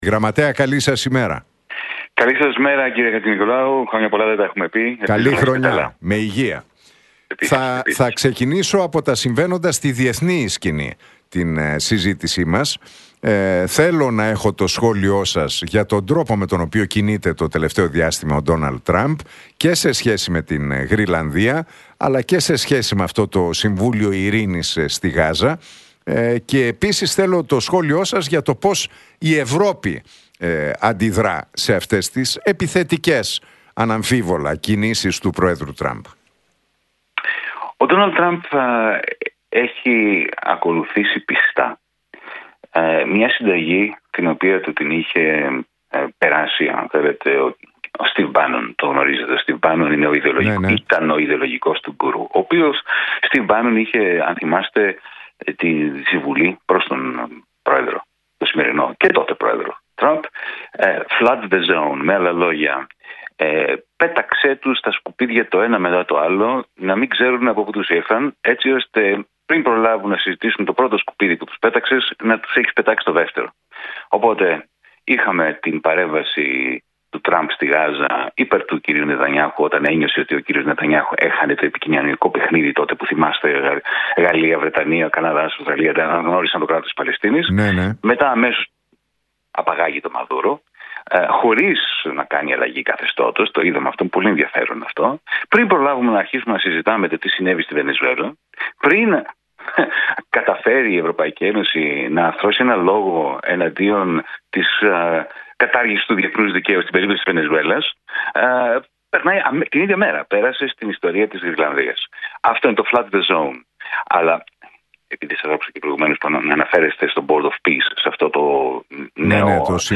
Για τις ενέργειες του Ντόναλντ Τραμπ, τις ελληνοτουρκικές σχέσεις και την εσωτερική πολιτική επικαιρότητα μίλησε ο Γιάνης Βαρουφάκης στον Realfm 97,8 και τον Νίκο Χατζηνικολάου.